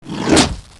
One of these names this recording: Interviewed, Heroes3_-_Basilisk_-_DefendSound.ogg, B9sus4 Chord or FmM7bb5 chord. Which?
Heroes3_-_Basilisk_-_DefendSound.ogg